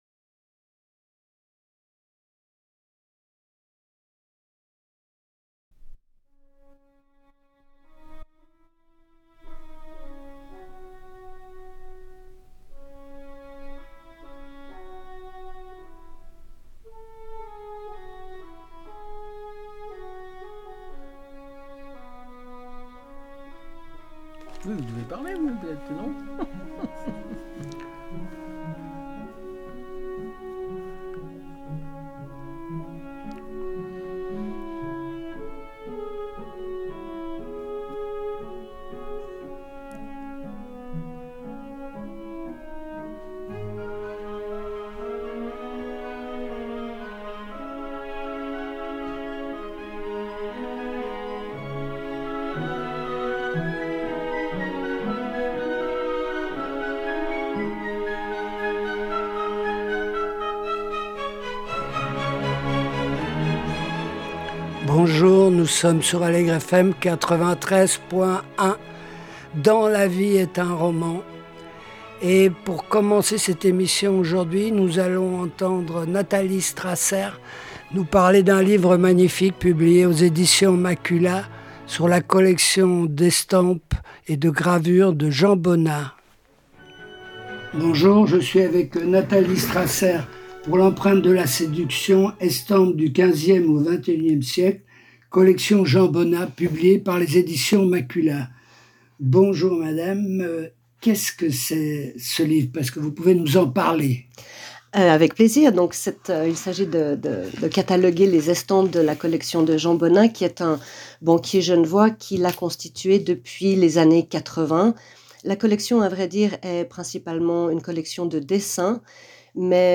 La vie est un roman # 17 décembre 2024 – Interview